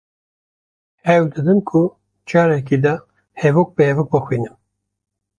Prononcé comme (IPA) /hɛˈvoːk/